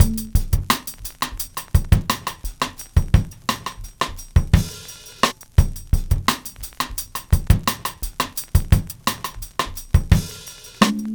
Index of /90_sSampleCDs/Best Service ProSamples vol.40 - Breakbeat 2 [AKAI] 1CD/Partition B/MEANGREEN086